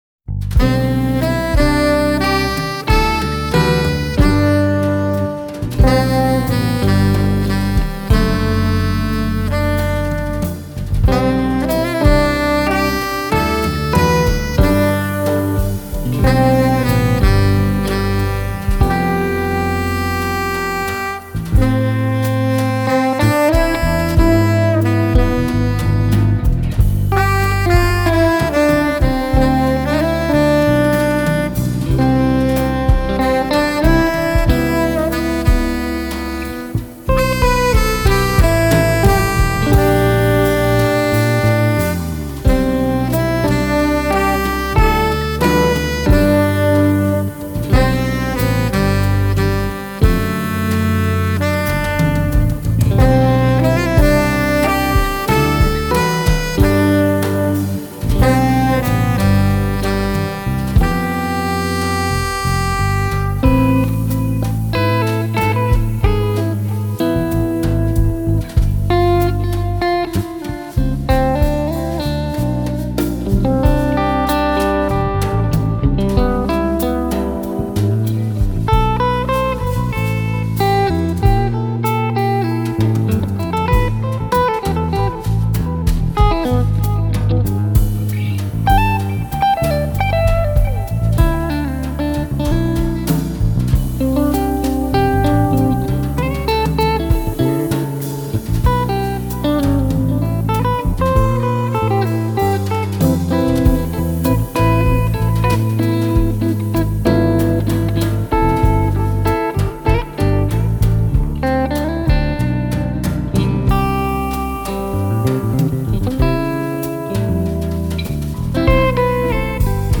sax
guitar
Bass
drums Écouter un extrait